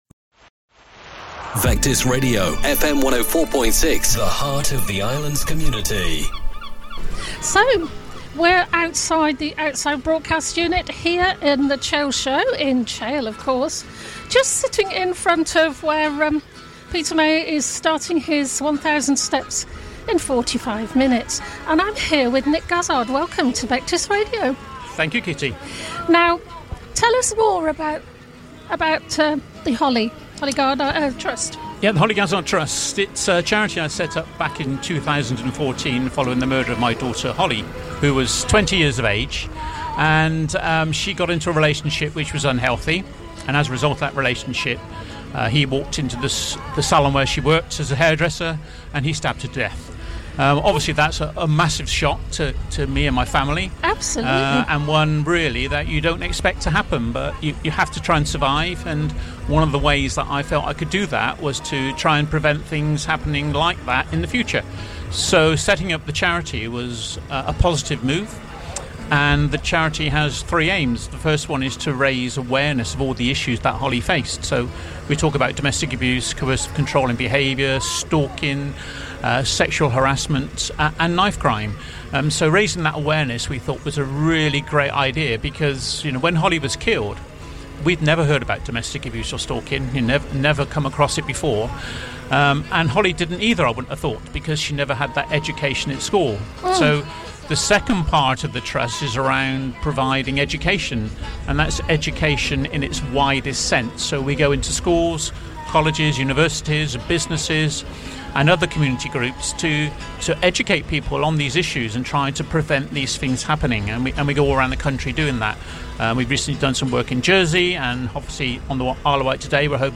at The Chale Show 2025